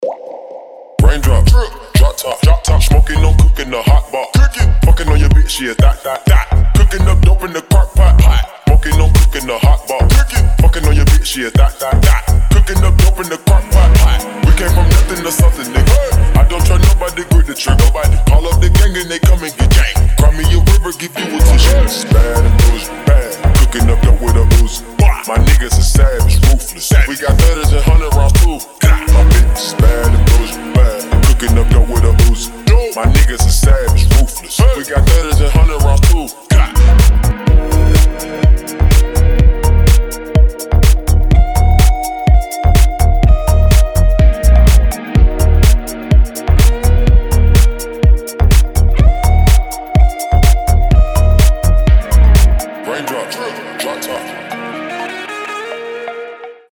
• Качество: 320, Stereo
мужской голос
remix
dance
Electronic
house
Бодрящий танцевальный рингтон!